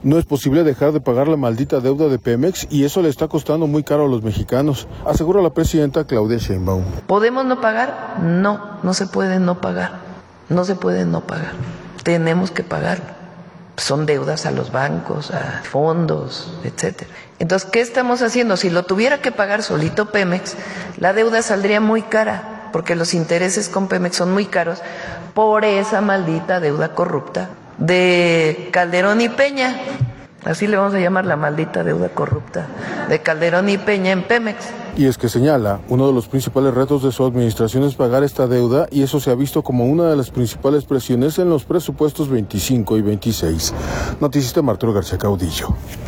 No es posible dejar de pagar la maldita deuda de Pemex y eso le está costando muy caro a los mexicanos, asegura la presidenta Claudia Sheinbaum.